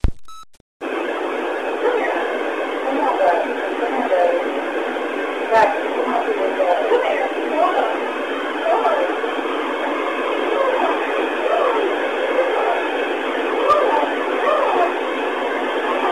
EVP6 Unfiltered
This EVP was recorded at a business in Buford, GA.